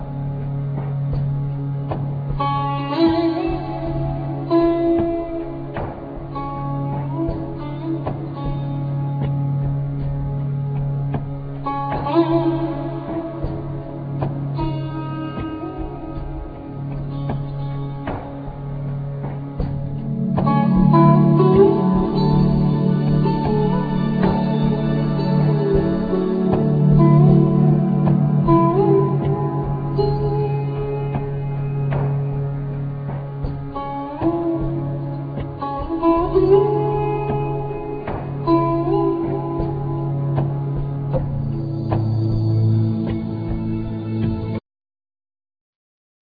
African drums
Indian drums
Accordion,Keyboards
Sitar,Surbahar,Keyboards